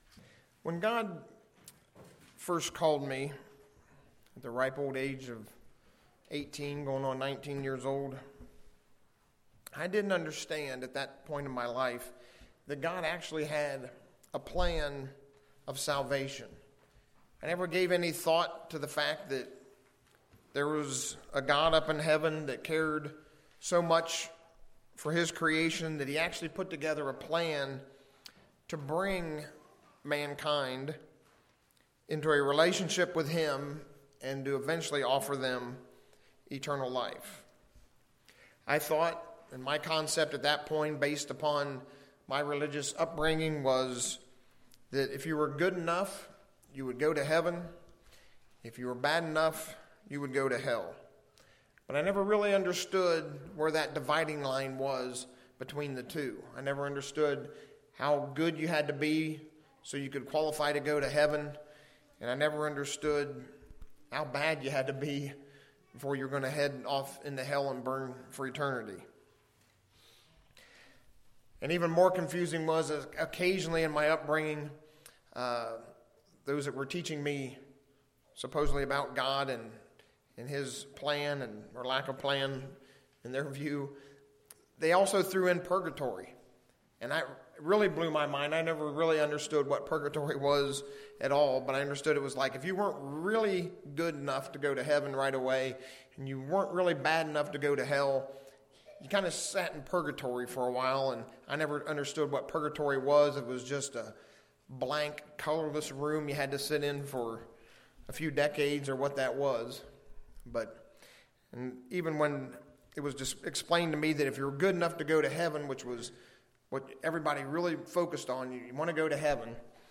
So what is the overall purpose of keeping the Days of Unleavened Bread? These days are centered around two different concepts which are explained in this sermon.
Given in Ft. Wayne, IN